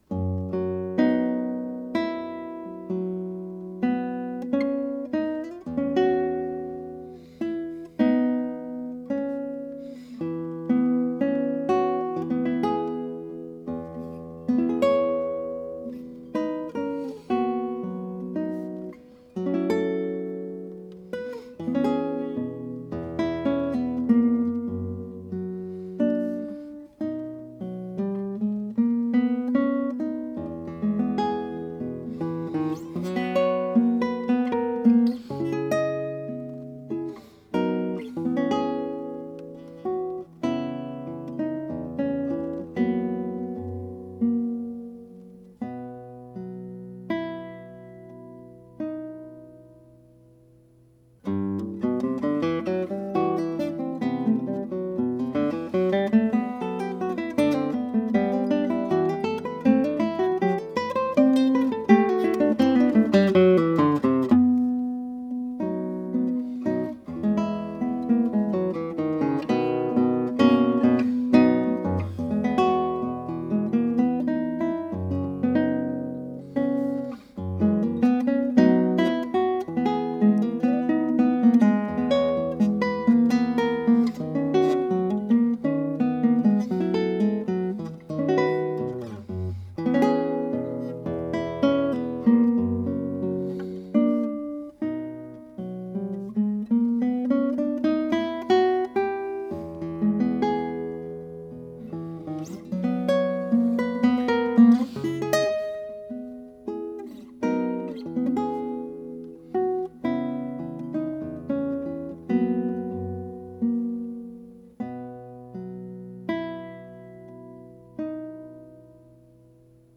21 short etude-like movements, total length ca. 27 minutes, written in 2013.